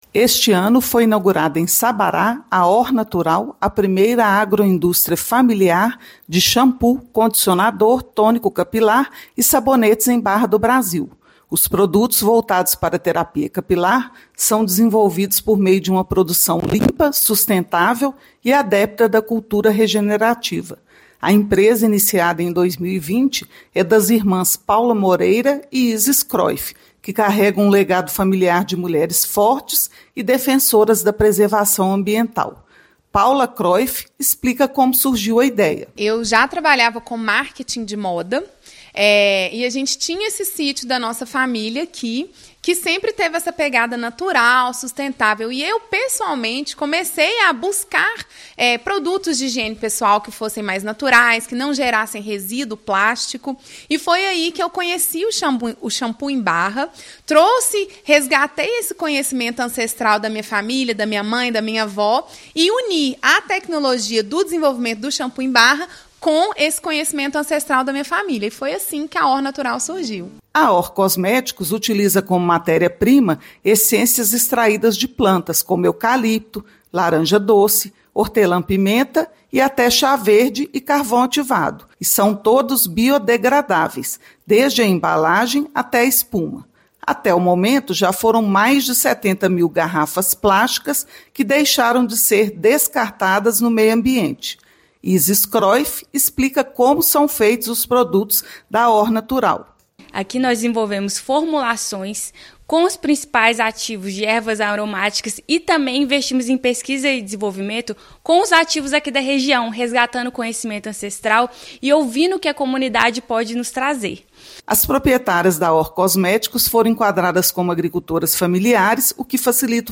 Empreendimento fica em Sabará, região metropolitana da capital mineira, e trabalha com produção sustentável, utilizando como matéria-prima essências extraídas de plantas. Ouça matéria de rádio.